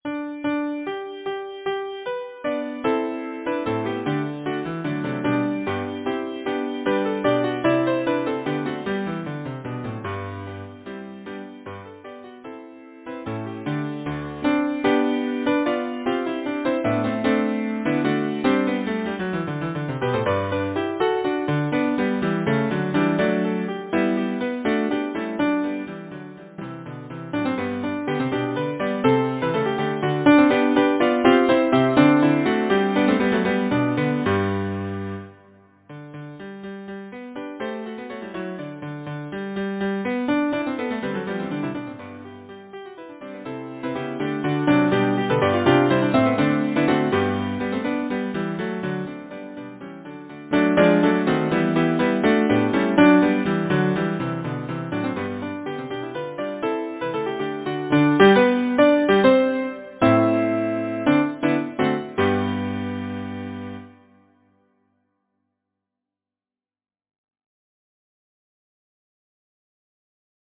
Title: Kitty of Coleraine Composer: Charles Harford Lloyd Lyricist: Edward Lysaght Number of voices: 4vv Voicing: SATB Genre: Secular, Partsong, Folksong
Language: English Instruments: A cappella
First published: 1909 Novello and Co. Description: Irish Air